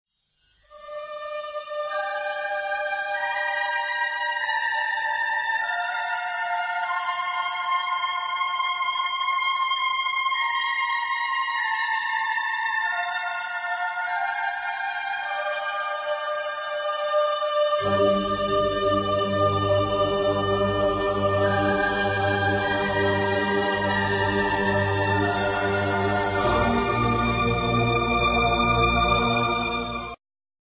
1988 exciting medium instr.